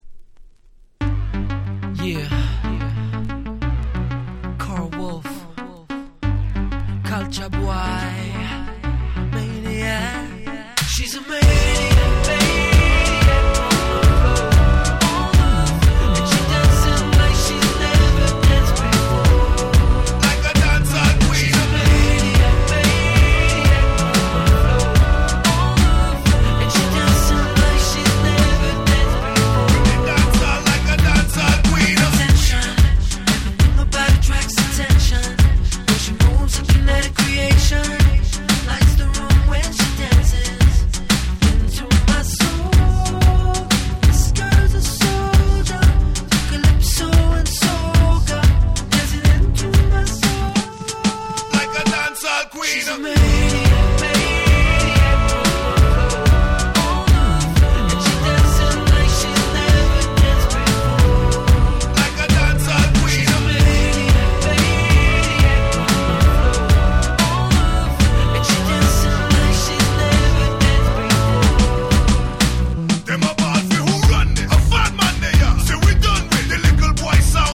夏ソング アゲアゲ EDM レゲエ Reggae 00's R&B